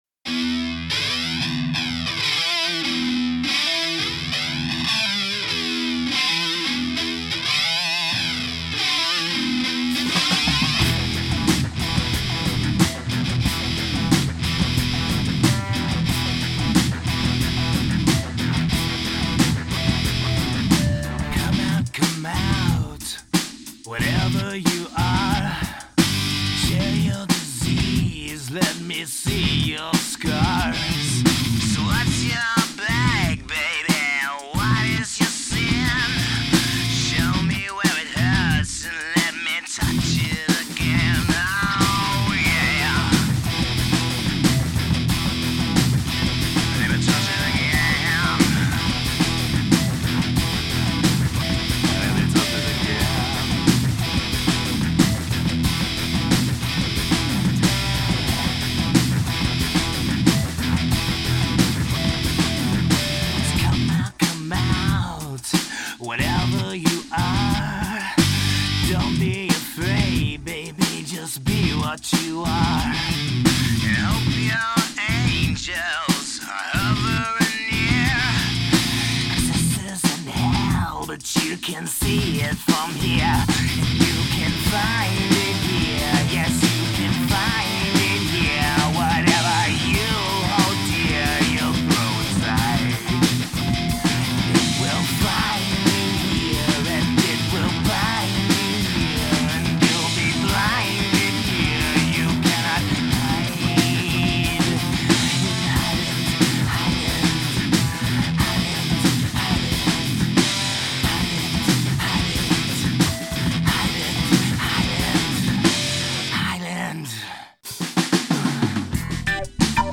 Combining elements of the last four decades of music, Popular and Underground, Hollow Doubt weaves a complicated web of sounds.
There is a Demo Quality sample of "
Wheels of Steel, Sampling
Lead Vocals, Rhythm/Acoustic Guitar
Bass Guitar/ Backing Vocals
Drums